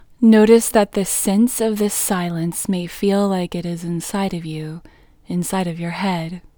WHOLENESS English Female 5